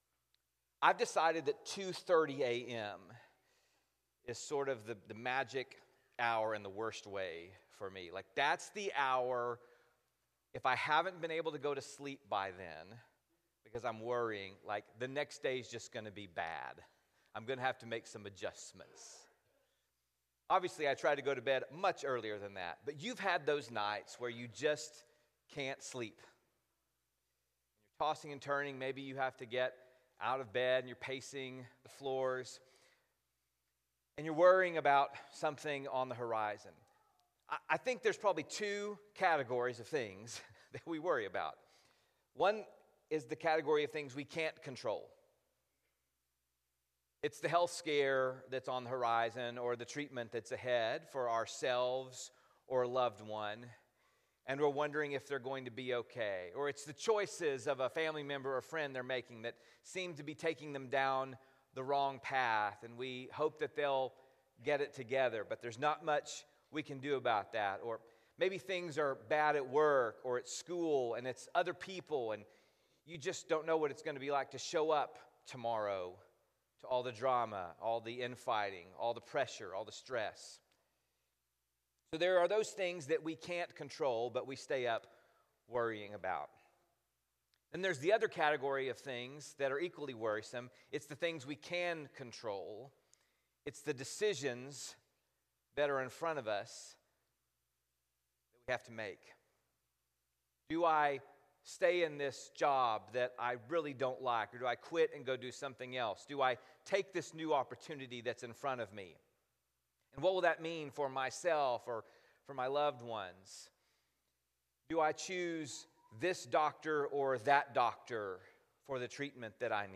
other speakers at the Preston Road Church of Christ in Dallas, Texas.